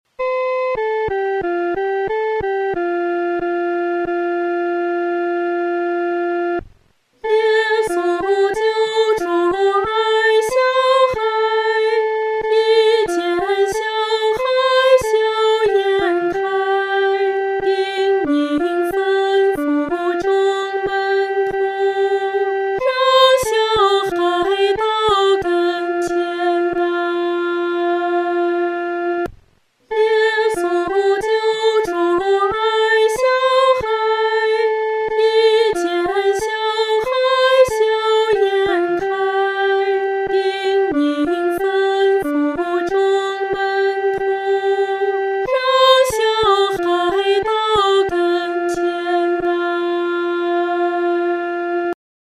合唱
女高
这首诗歌可用较流动的中速来弹唱。